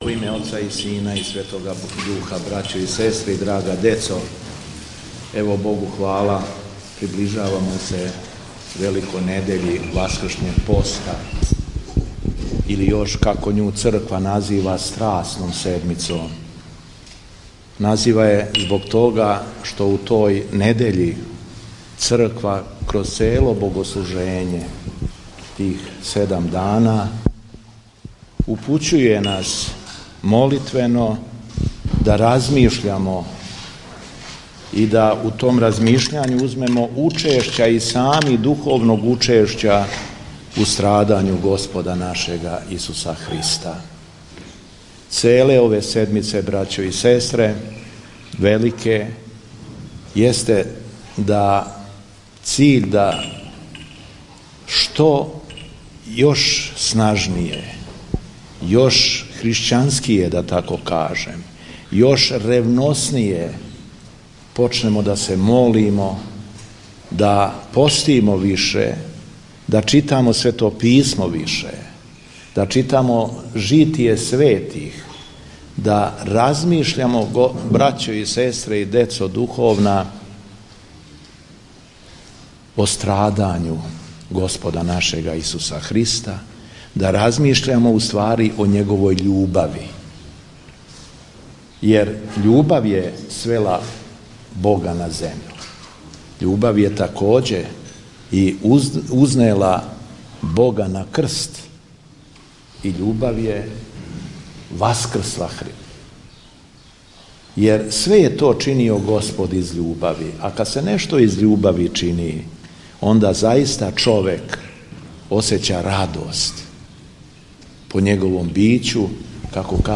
Беседа Његовог Преосвештенства Епископа шумадијског г. Јована
Епископ Јован је верне поучио својом беседом.